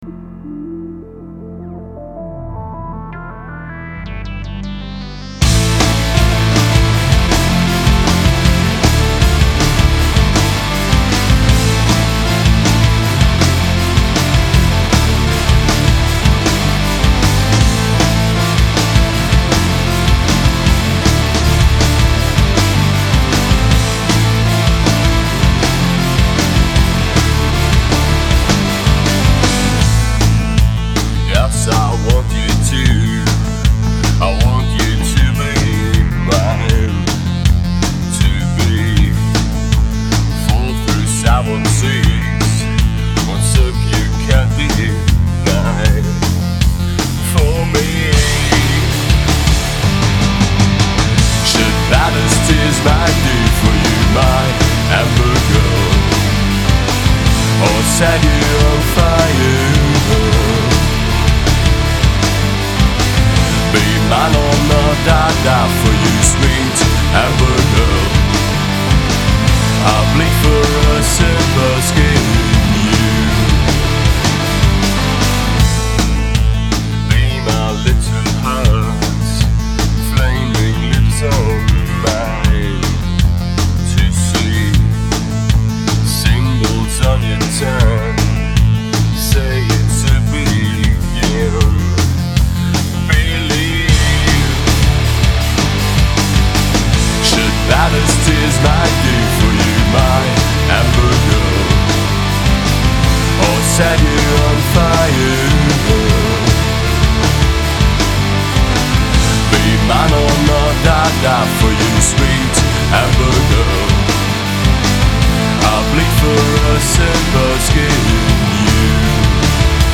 这就是来自德国的死亡/歌特/厄运金属乐团。